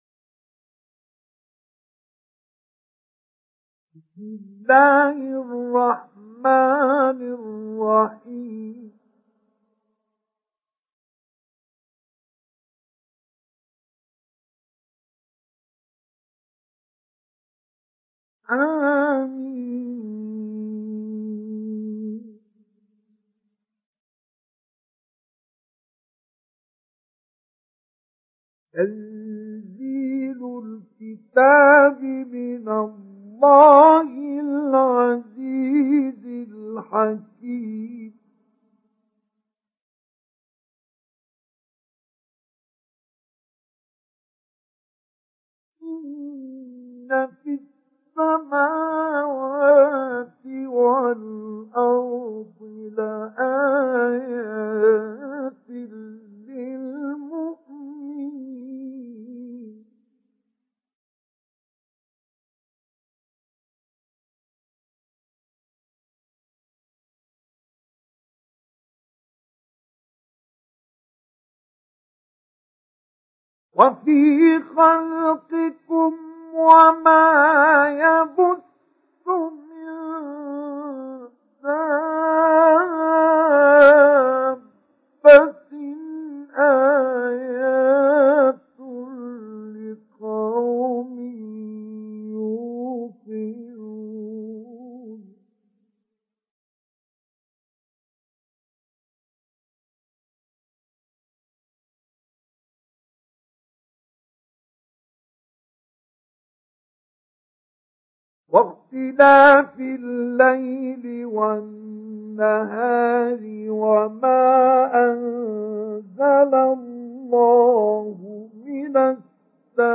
Surat Al Jathiyah Download mp3 Mustafa Ismail Mujawwad Riwayat Hafs dari Asim, Download Quran dan mendengarkan mp3 tautan langsung penuh
Download Surat Al Jathiyah Mustafa Ismail Mujawwad